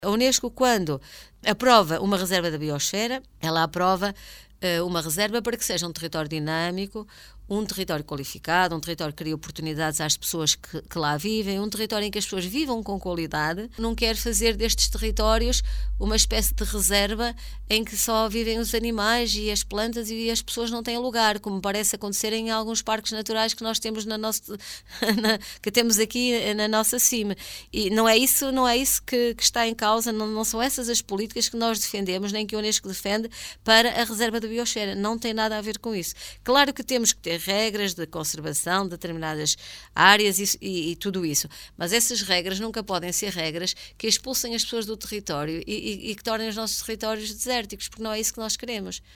A também autarca de Alfândega da Fé, Berta Nunes, esclarece que a Reserva da biosfera da Meseta Ibérica vai ser um território gerido de forma a contribuir para o seu desenvolvimento.